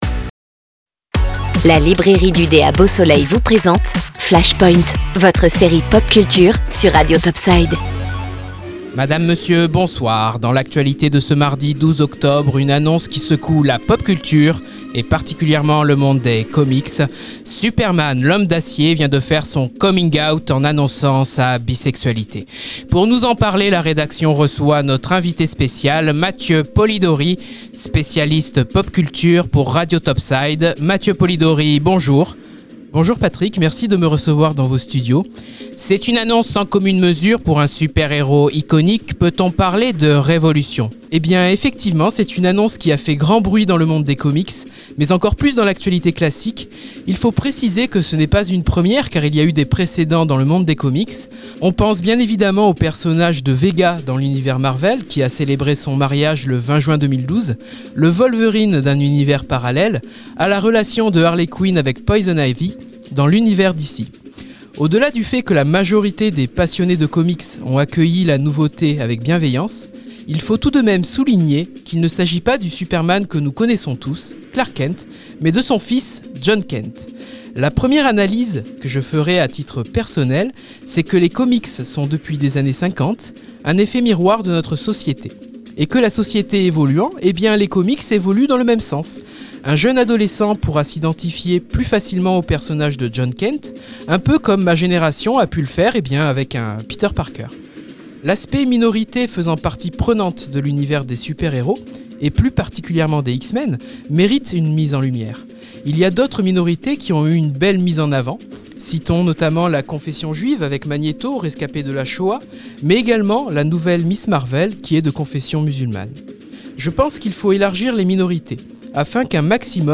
Le journal de 20h comme si vous y étiez, concernant ce fait de société : Superman est bi-sexuel. Coup de tonnerre dans les comics et sur Flashpoint.